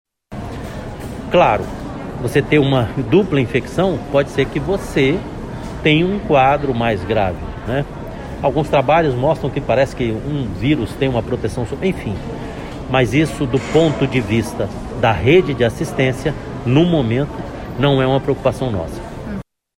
Mas o secretário de Saúde do Estado, Anoar Samad, diz que ainda não há motivo para pânico.
Sonora-Anoar-Samad-Secretario-de-Saude-do-Amazonas.mp3